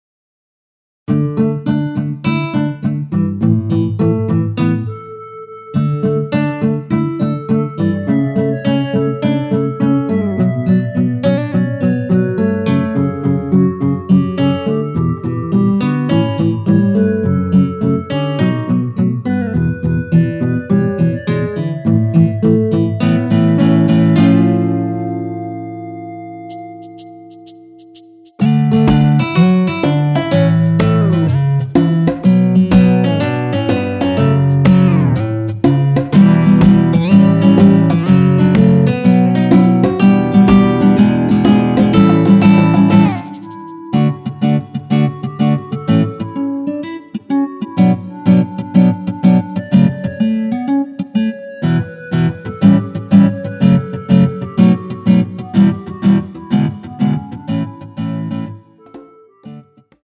전주 없이 시작 하는곡이라 노래 하시기 편하게 전주 2마디 많들어 놓았습니다.(미리듣기 확인)
원키에서(-2)내린 멜로디 포함된 MR입니다.
앞부분30초, 뒷부분30초씩 편집해서 올려 드리고 있습니다.
중간에 음이 끈어지고 다시 나오는 이유는